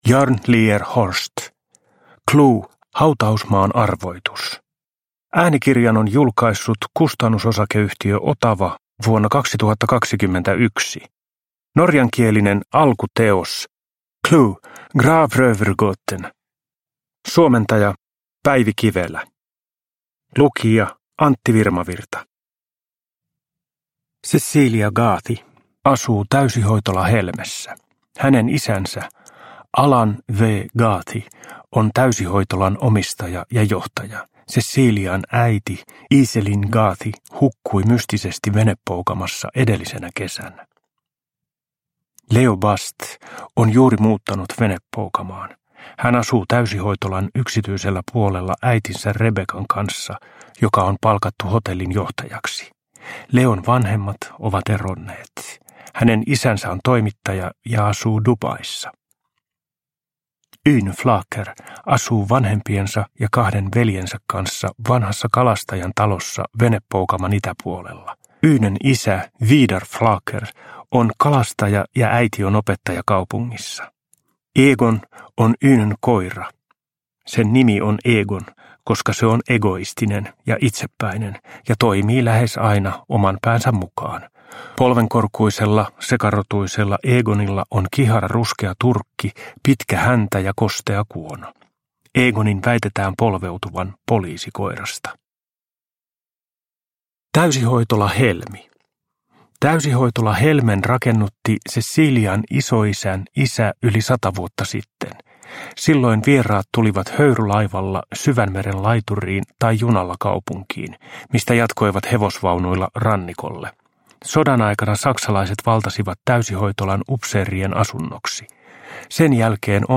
CLUE - Hautausmaan arvoitus – Ljudbok – Laddas ner